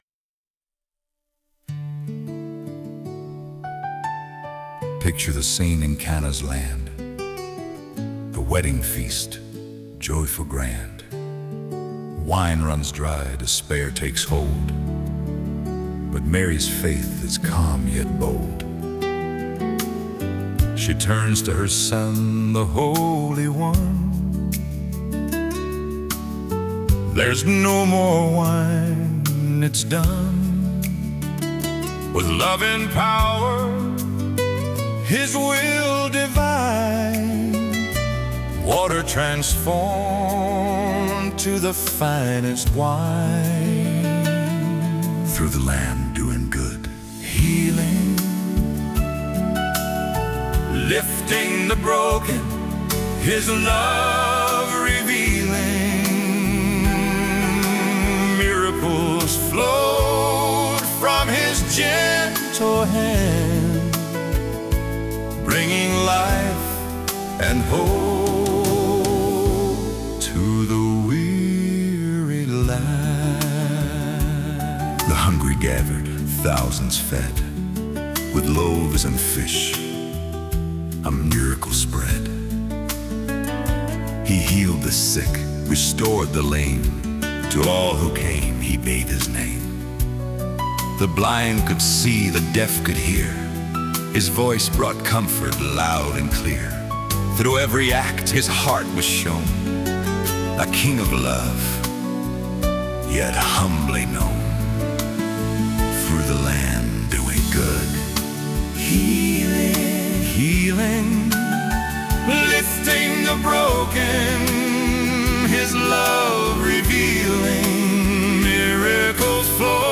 Encouraging and emotional Songs